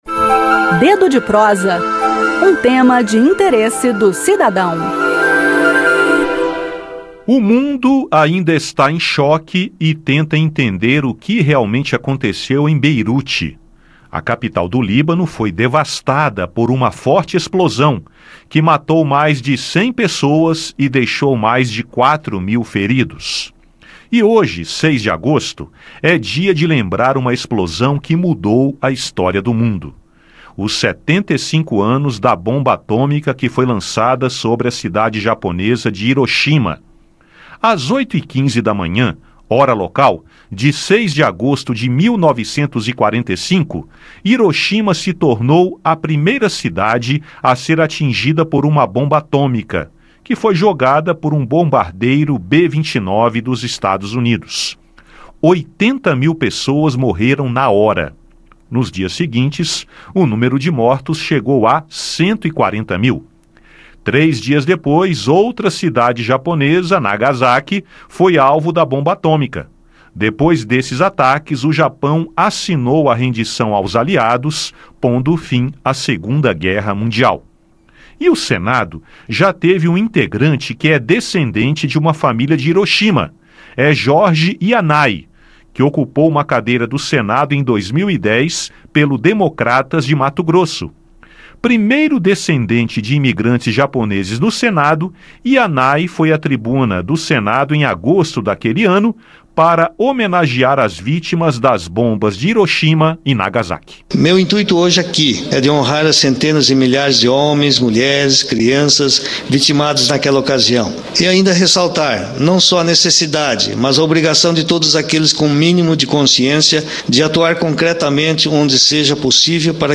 bate-papo